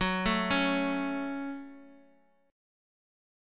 Gbm Chord
Listen to Gbm strummed